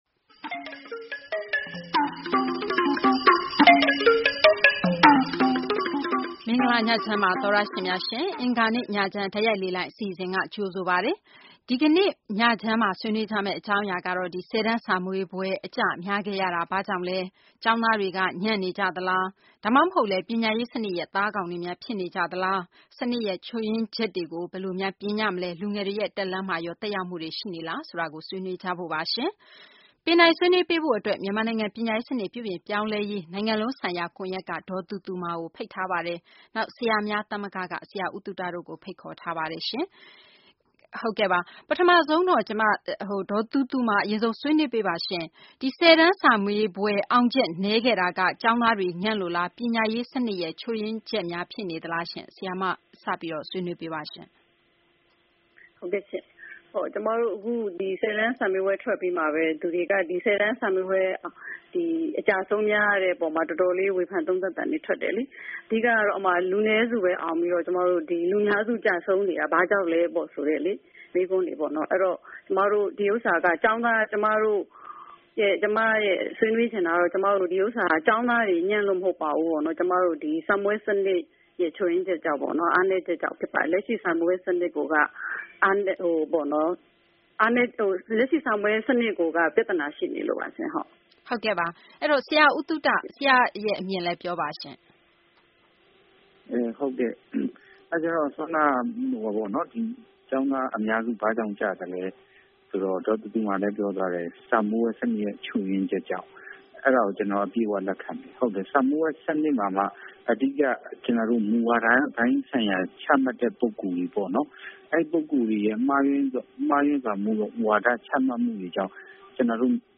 အလွတ်ကျက်ဖြေ ပညာရေး စနစ်ကို စဉ်းစား ဝေဖန် သုံးသပ် ဆင်ခြင်နိုင်စွမ်း မြှင့်တင်ပေးတဲ့ စနစ်တရပ်ဖြစ်လာရေး ပြုပြင် ပြောင်းလဲဖို့ လိုအပ်ချက်တွေကို ဆွေးနွေးထားတဲ့ အင်္ဂါနေ့ ညချမ်းတိုက်ရိုက်လေလှိုင်း အစီအစဉ်ကို နားဆင်နိုင်ပါတယ်။